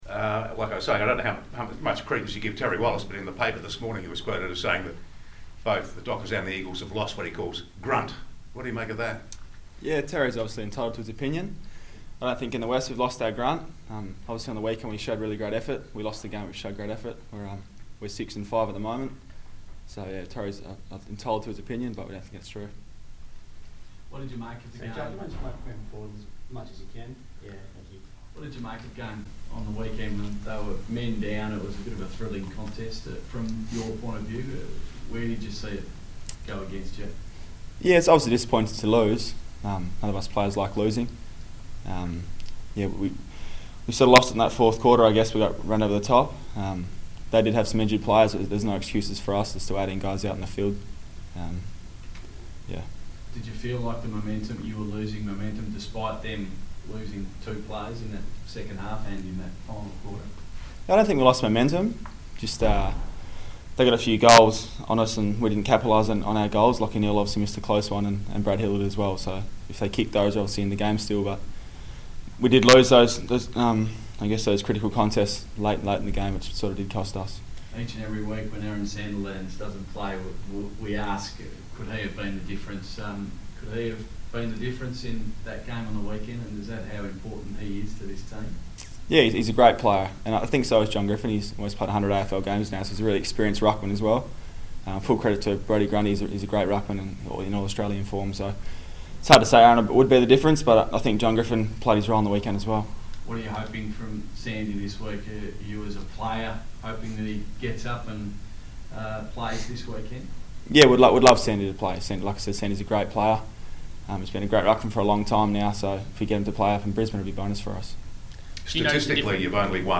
Joel Hamling media conference - 6 June 2017